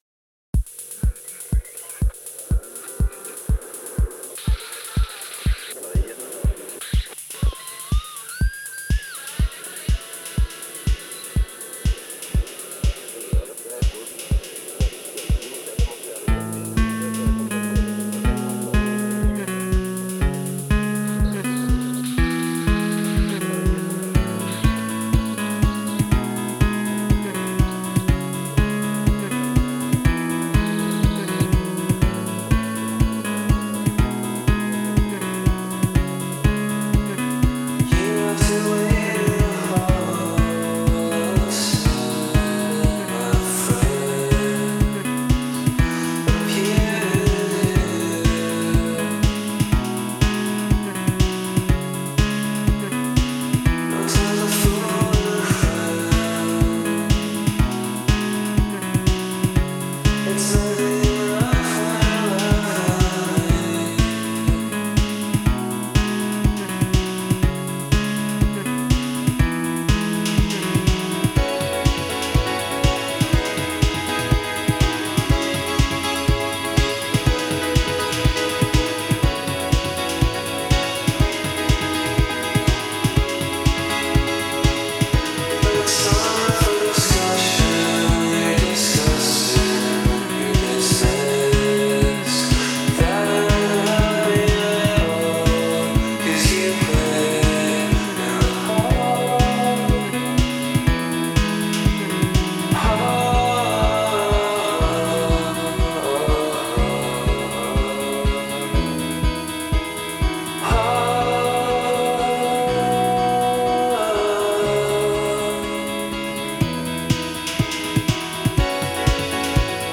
con la chitarra più in evidenza